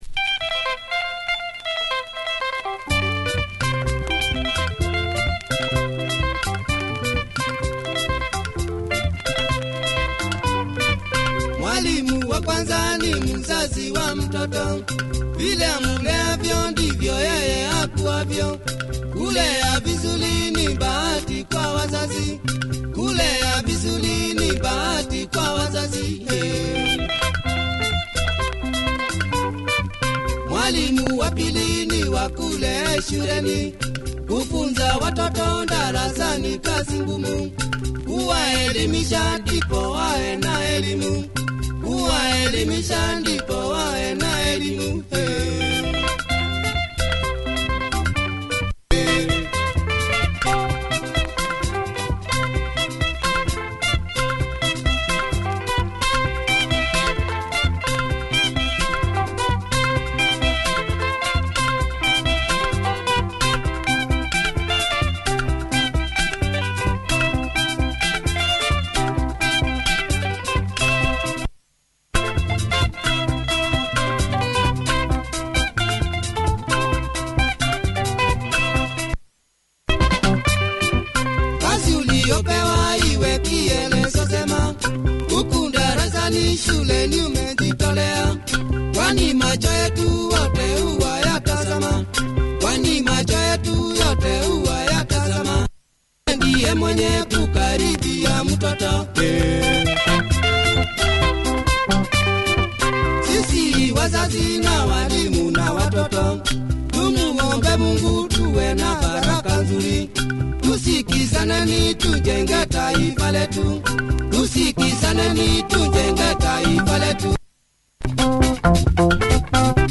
Nice benga
cool breakdown on part-2